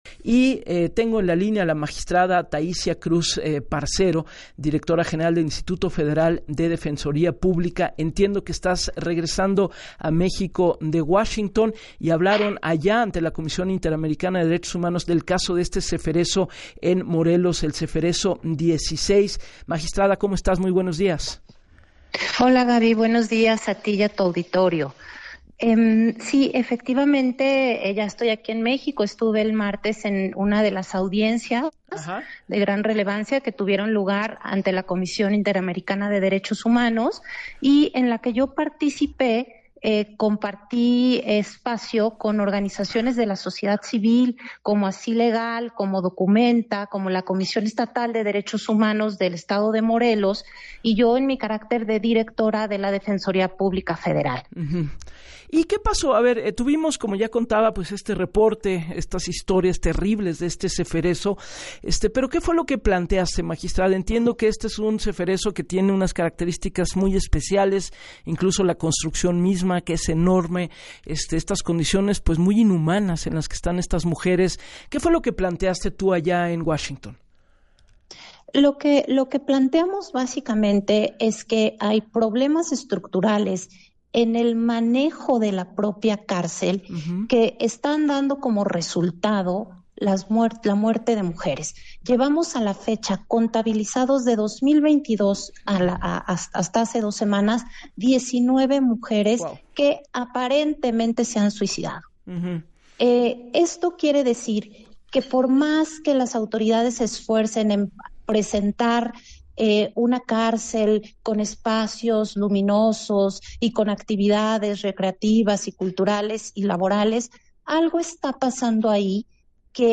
Luego de participar en la Corte Interamericana de Derechos Humanos, y exponer la situación violatoria de los derechos de las mujeres, que prevalece en el Cefereso 16 de Morelos, la directora General del Instituto Federal de Defensoría Pública, Taissia Cruz Parcero, compartió en entrevista para “Así las Cosas” con Gabriela Warkentin, cómo las autoridades del penal, niegan su responsabilidad en la muerte de 19 mujeres al interior de sus instalaciones, a lo que dijo: “No podemos aceptar que las autoridades penitenciarias desplacen las responsabilidades que tienen”.